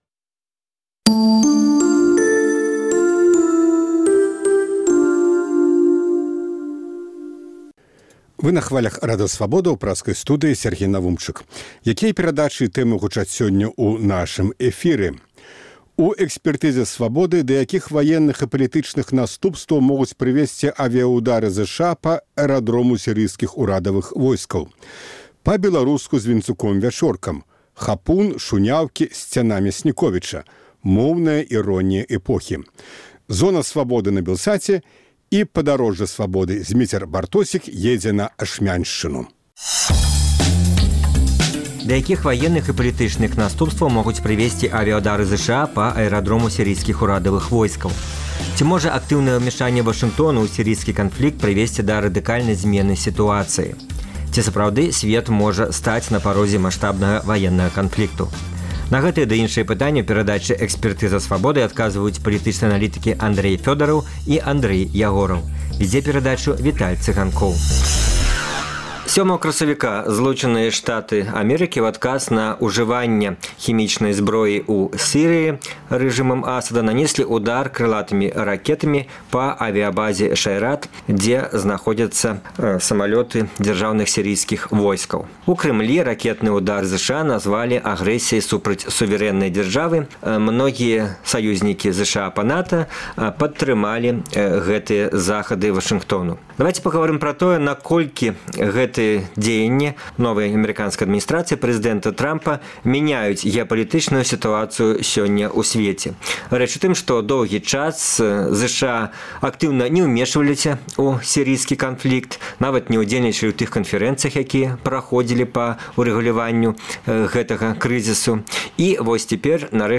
На гэтыя ды іншыя пытаньні ў перадачы Экспэртыза Свабода адказваюць палітычныя аналітыкі